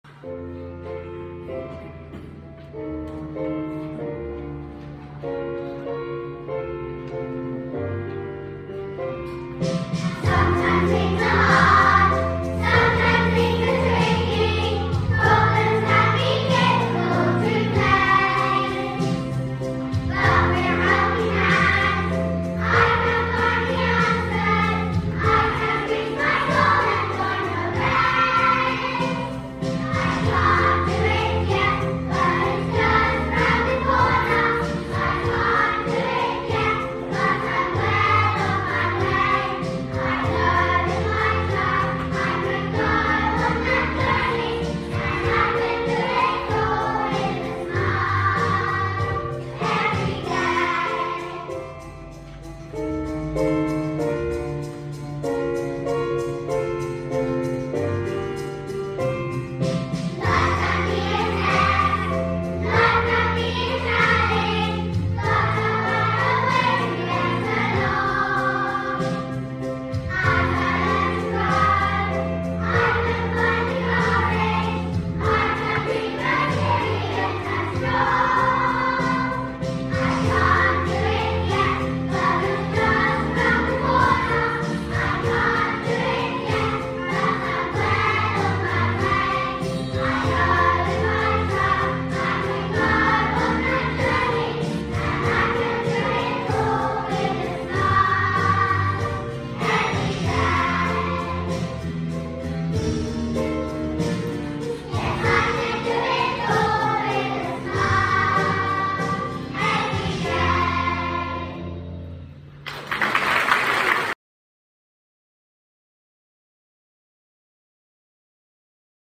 I Can't Do it Yet | Junior Choir